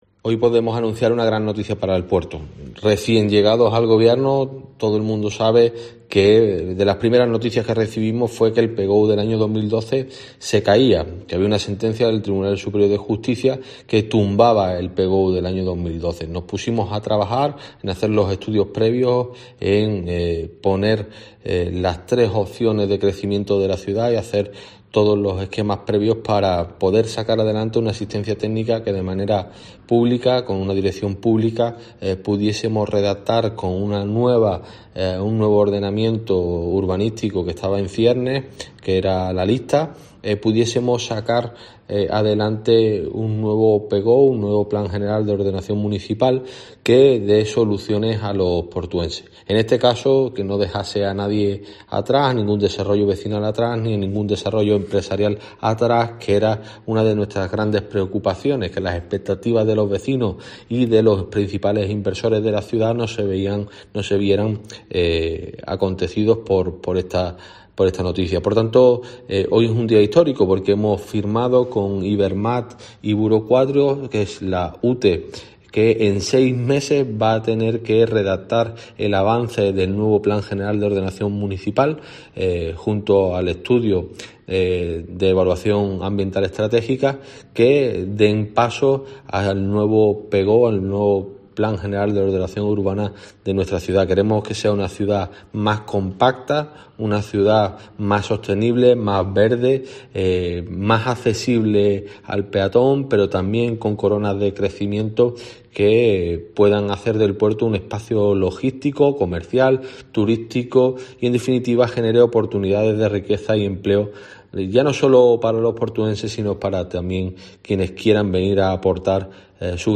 Germán Beardo, alcalde de El Puerto de Santa María (Cádiz) explica cómo ha sido el proceso